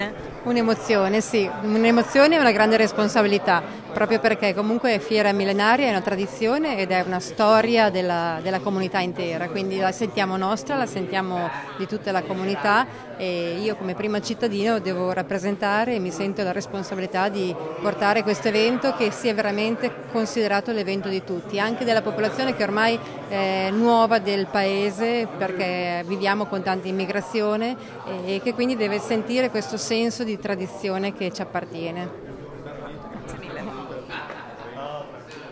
Conferenza stampa di presentazione della Fiera Millenaria di Gonzaga, edizione 2019
Al nostro microfono anche il neo sindaco di Gonzaga, Elisabetta Galeotti:
sindaco-gonzaga.m4a